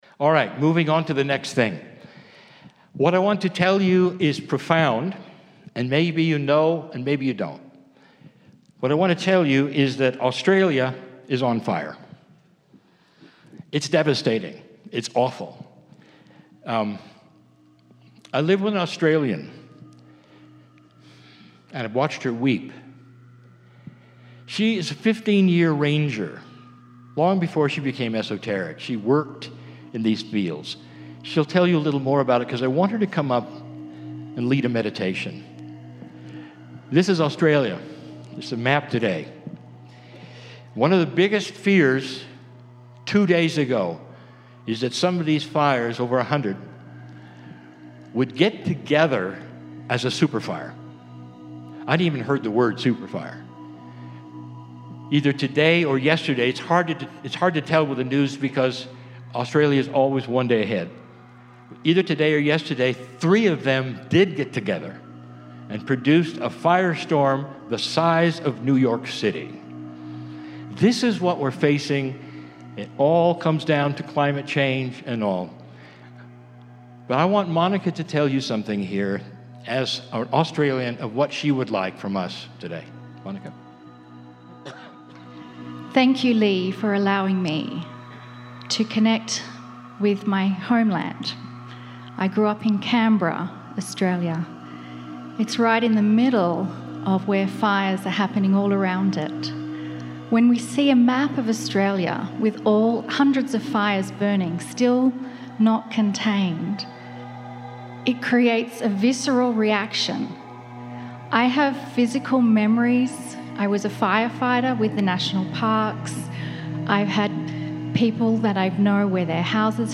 Annual Meeting Saturday & Sunday
KRYON CHANNELLING